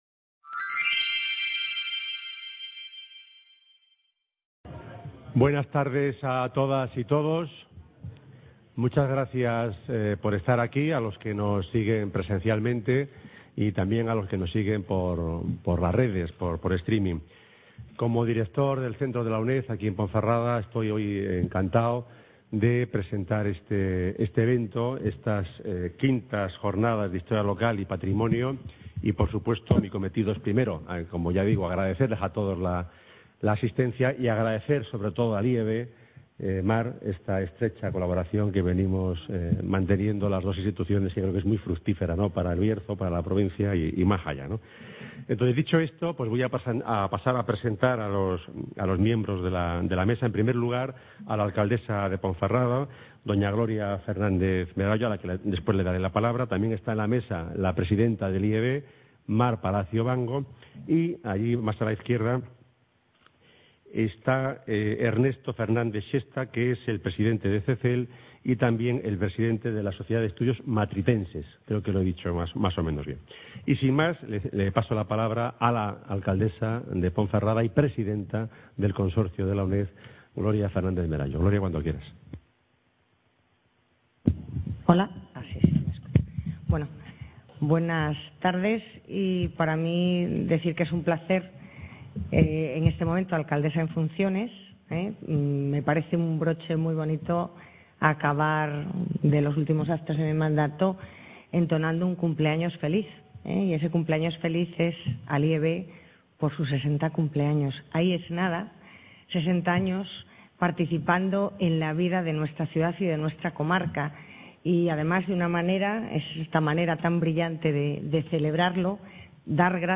Inauguración de las V Jornadas de Historia Local y…